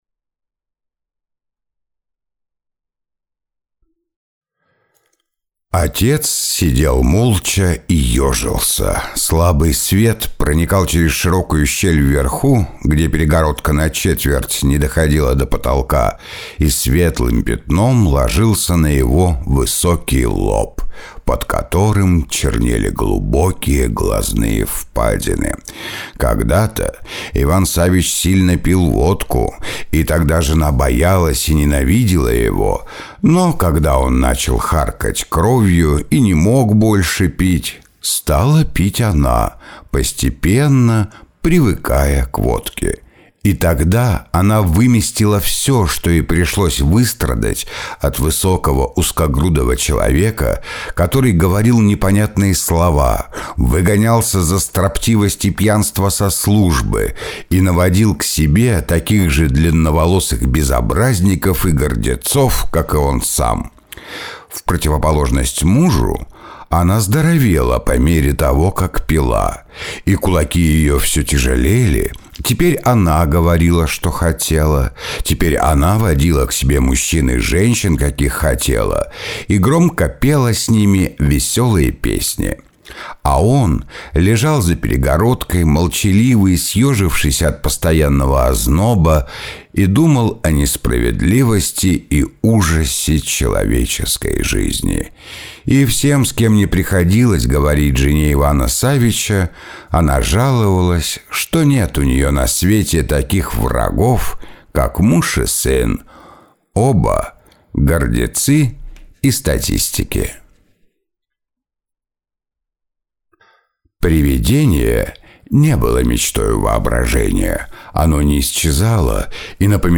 чтение-фрагменты
Муж, Аудиокнига/Зрелый